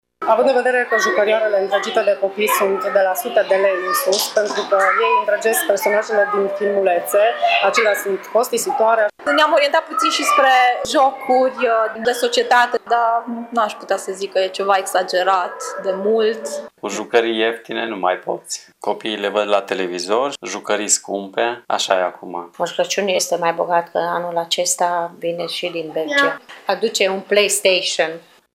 Moșul a primit scrisorile copiilor iar părinții speră ca micuții să nu fie dezamăgiți: